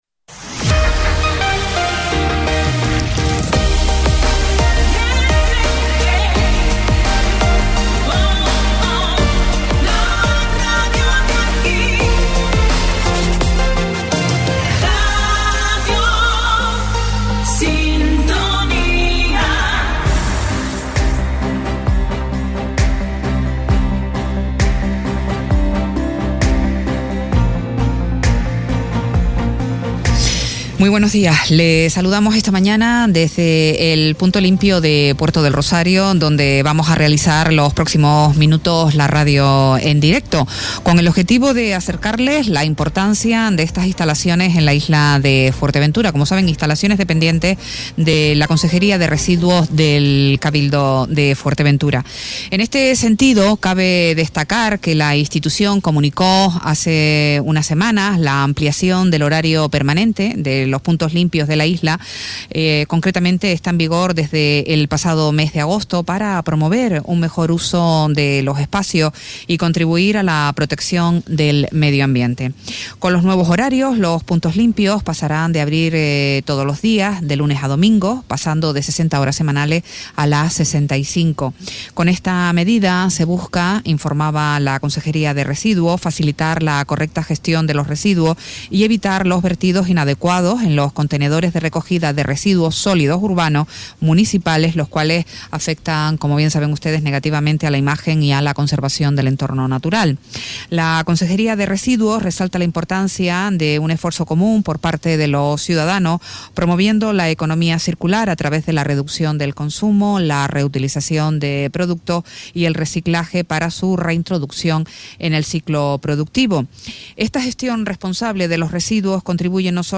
Radio Sintonía se traslada hasta el Punto Limpio de Puerto del Rosario, situado en Risco Prieto, para conocer el funcionamiento de estas instalaciones.
Entrevistas